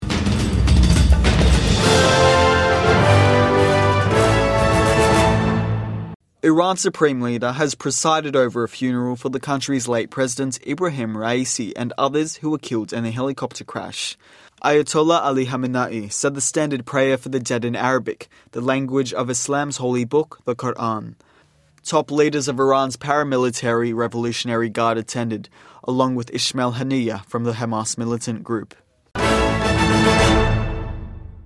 Iran's supreme leader at funeral of country's late president Ebrahim Raisi